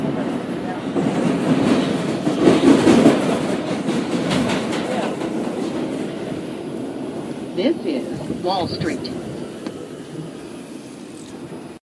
描述：火车的门在车站打开和关闭。
标签： 火车 旅游 公告
声道立体声